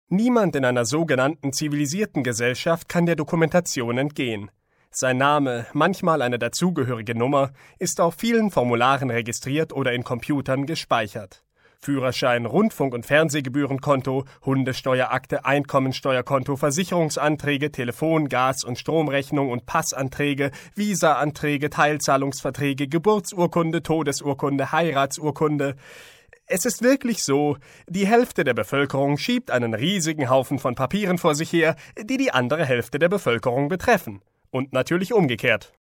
Sprechprobe: Sonstiges (Muttersprache):
german voice over talent, computer-gemes, audiobooks ...